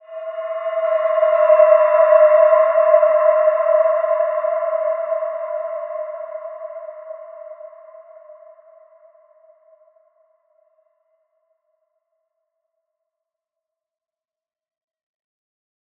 Wide-Dimension-E4-f.wav